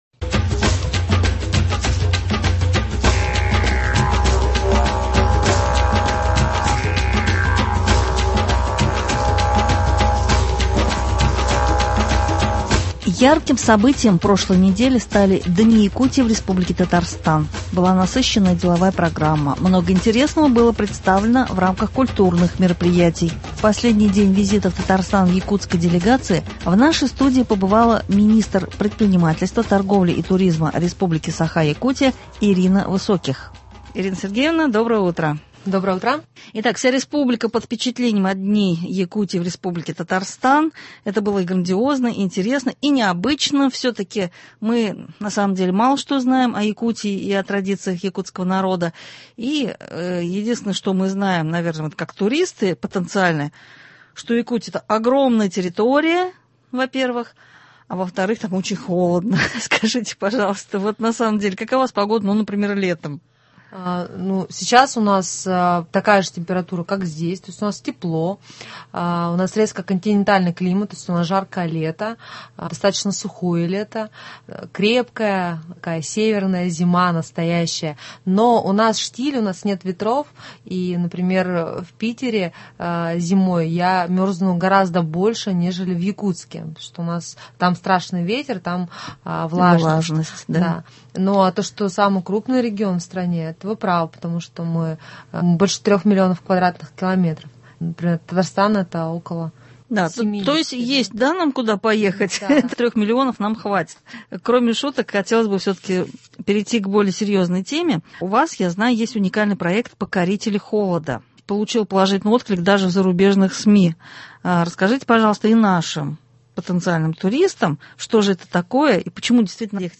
В последний день визита в Татарстан якутской делегации в нашей студии побывала министр предпринимательства, торговли и туризма Республики Саха (Якутия) Ирина Высоких.